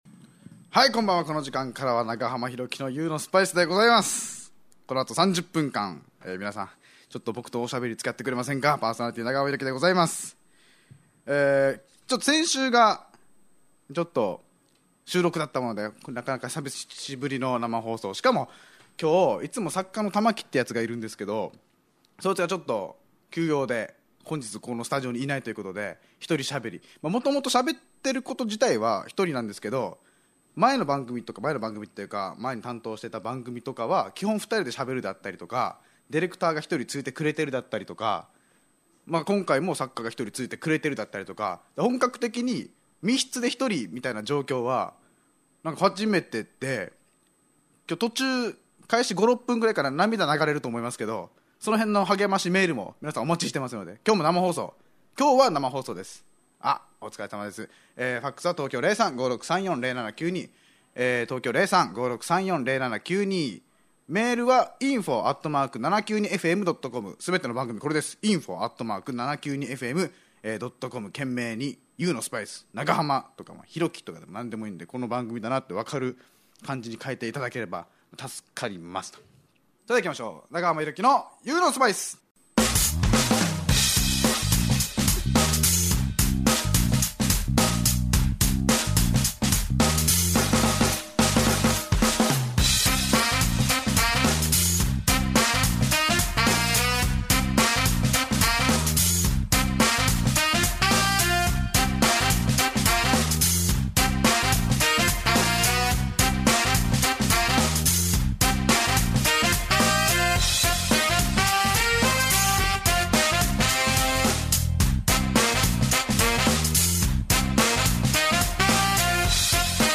脳みそ震撼、不敵なトークバラエティ！あなたの暮らしの香辛料になれたら・・・ vol.４「夢のような沖縄帰省からトーキョーリターン。